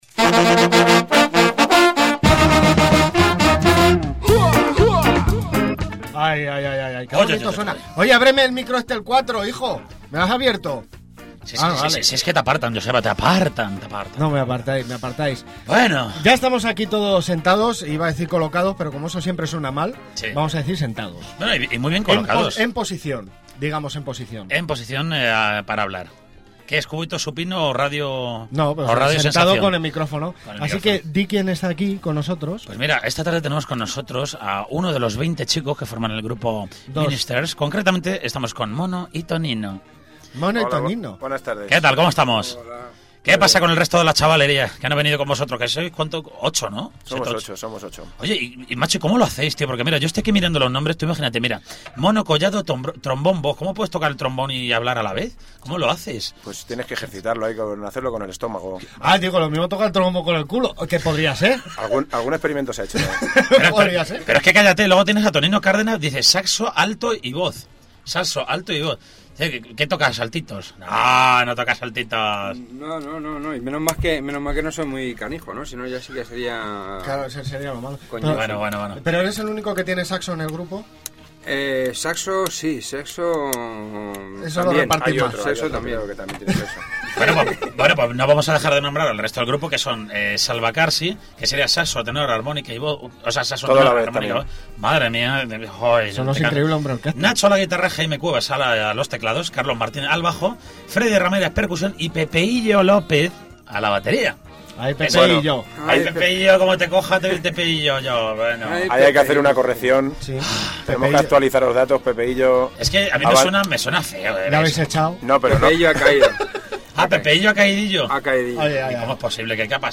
Entrevista a Los Ministers del Ronsteady
Entrevista_a_The_Ministers-2.mp3